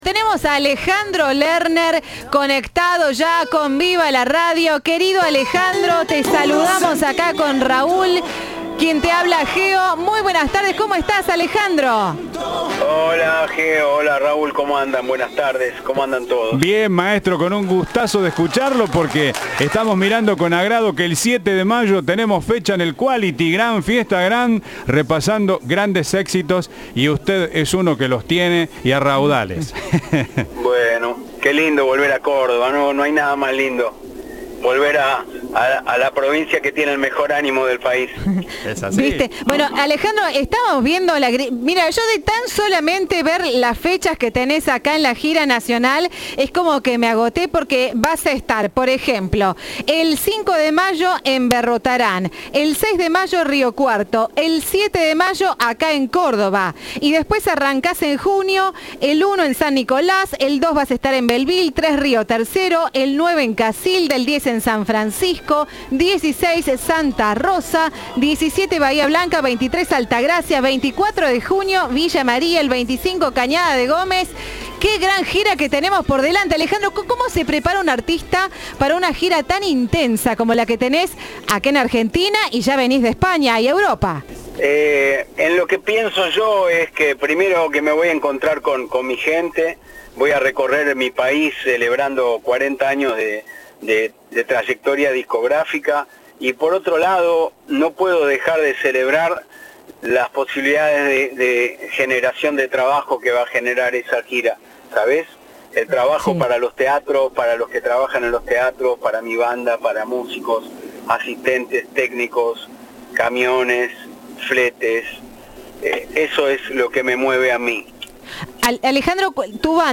Entrevista de Viva la Radio.